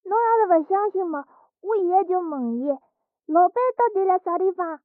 三楼/囚室/肉铺配音偷听效果处理